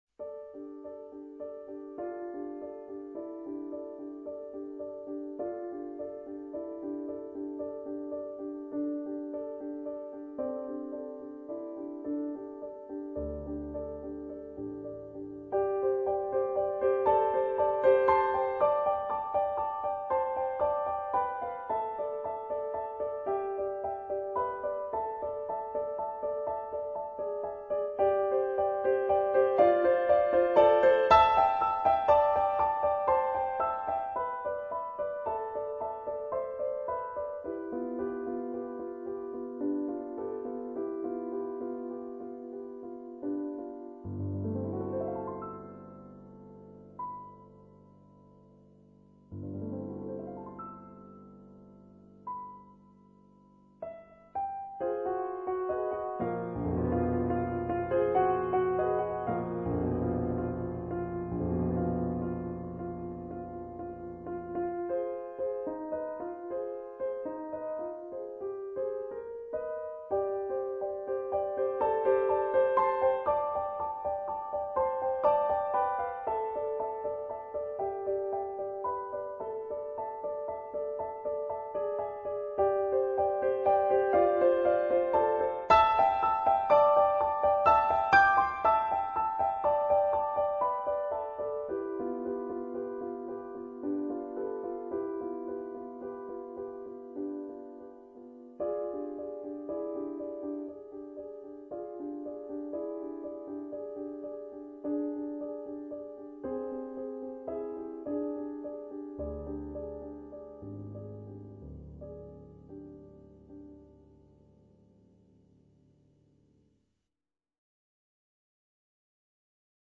Instrument(s): piano solo